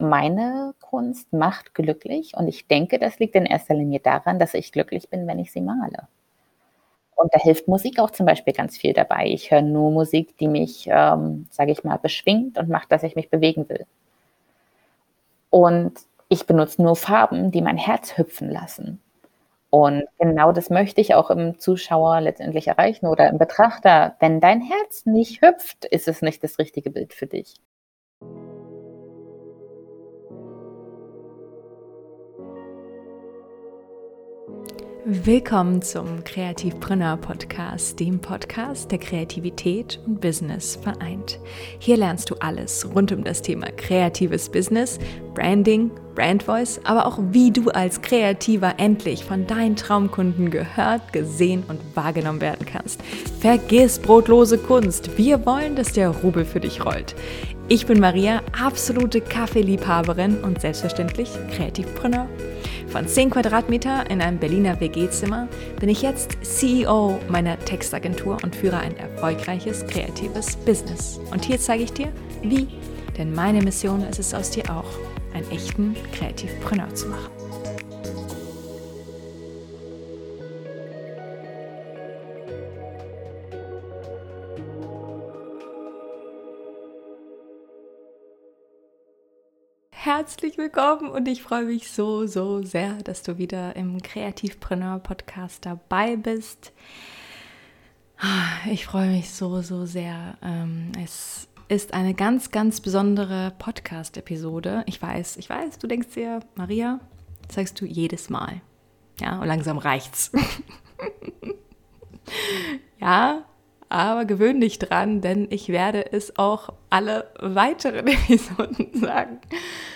Ein Gespräch über Kunst, Ablehnung, Auf, Abs und Erfüllung.